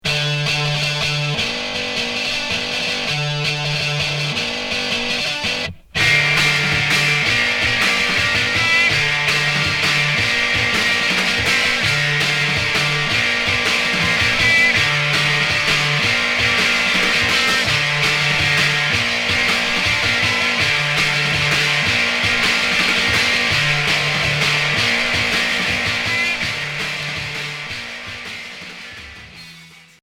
Garage noise